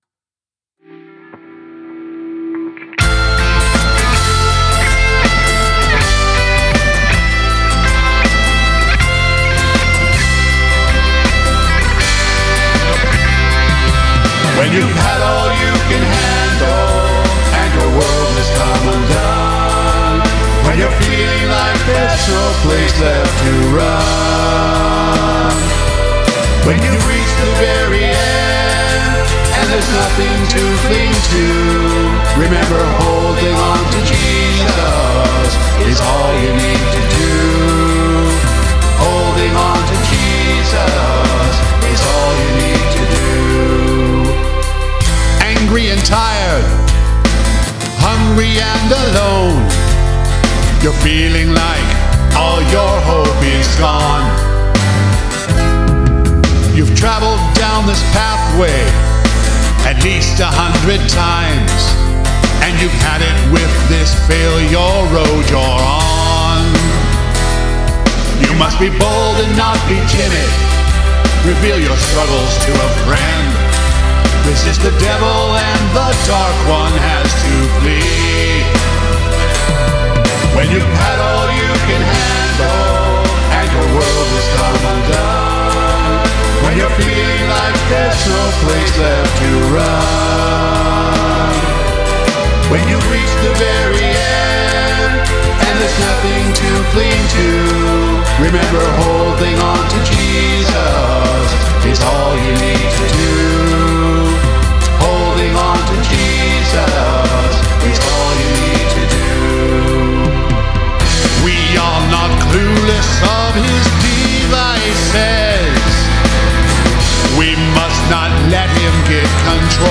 Here's my first rough mix of a new song I wrote.